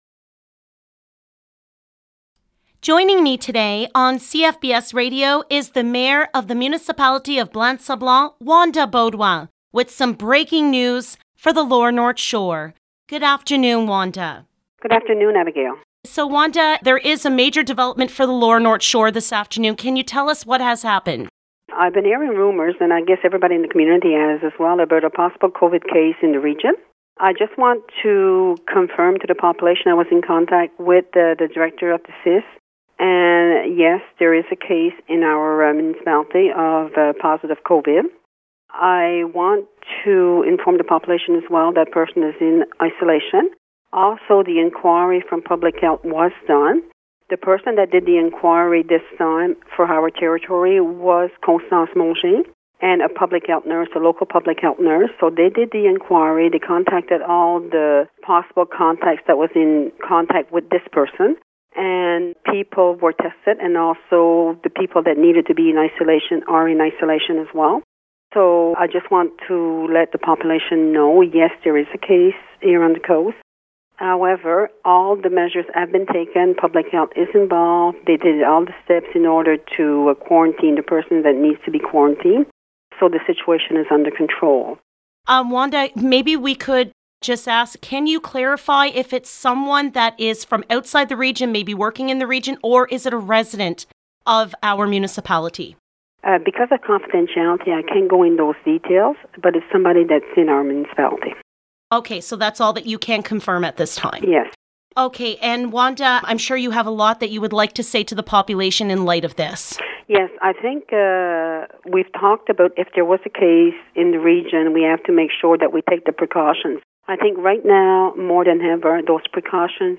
* Listen to the interview with Mayor Beaudoin by clicking on the sound files below (click on the speaker icon next to the sound file and a media player will open up to hear the interview).